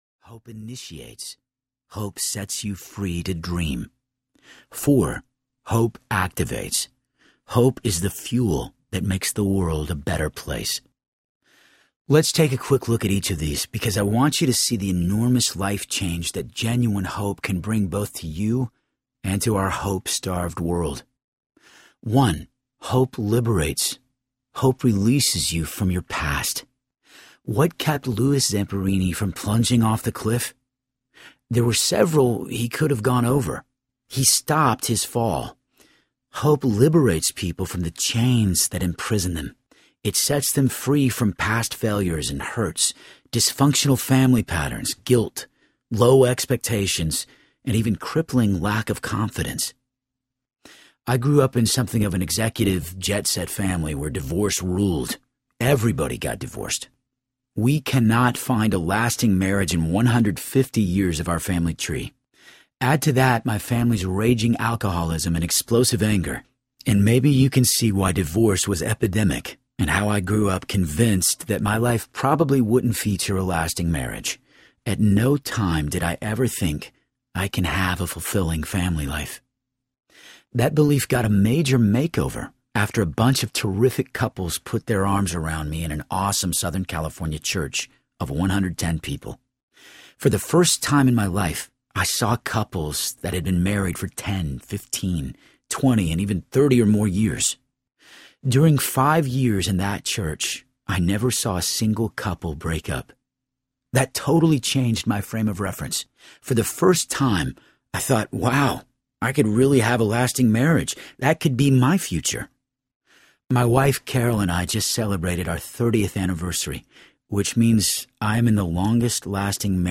The Hope Quotient Audiobook
Narrator
7.55 Hrs. – Unabridged